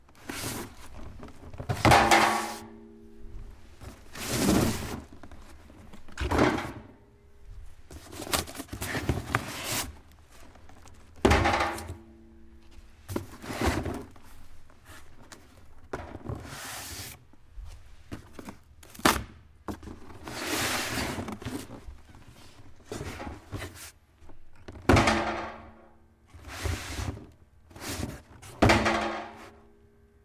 OWI箱落在地上
描述：落在地板上的酒盒的声音。
标签： 滑动 响亮 纸板 脱落 坠落 幻灯片 摩擦
声道立体声